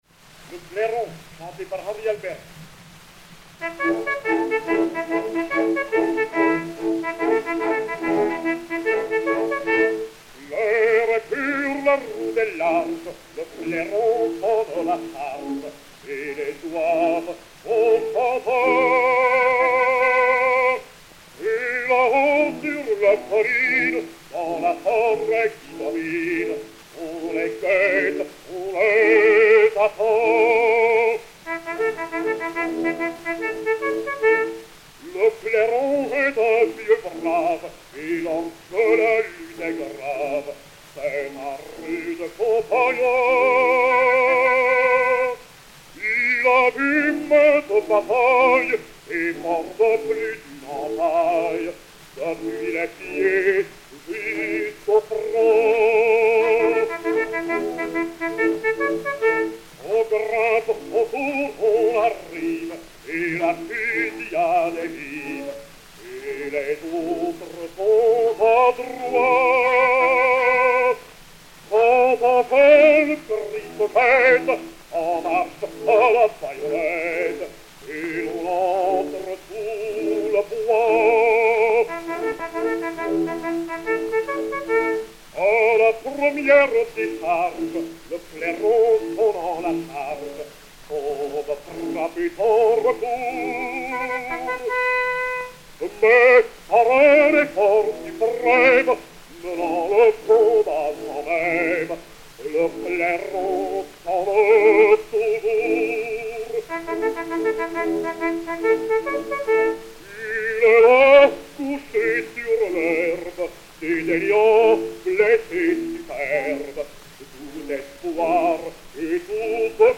Henri Albers et Orchestre